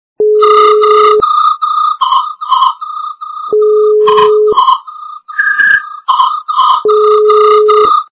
» Звуки » Природа животные » Звук - Сверчка (Cicada)
При прослушивании Звук - Сверчка (Cicada) качество понижено и присутствуют гудки.
Звук Звук - Сверчка (Cicada)